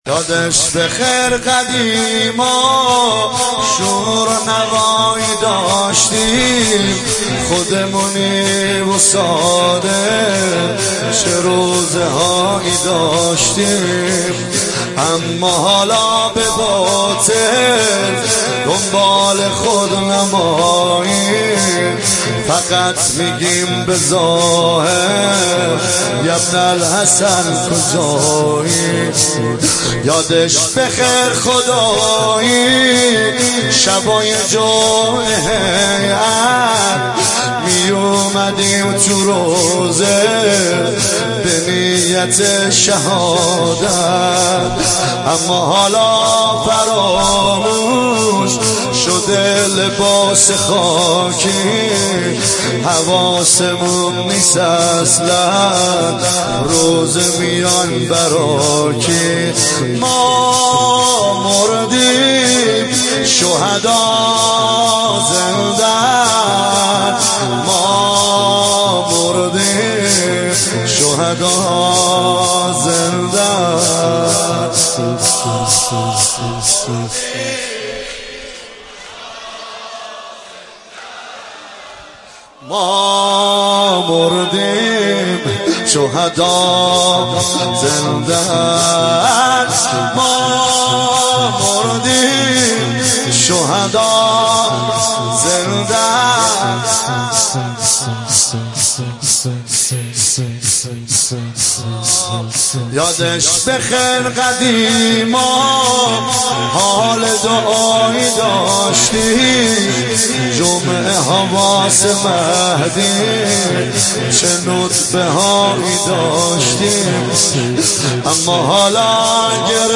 مداحی اربعین
شور